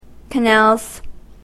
/kʌˈnælz(米国英語)/